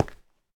stone4.ogg